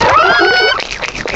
cry_not_gastrodon.aif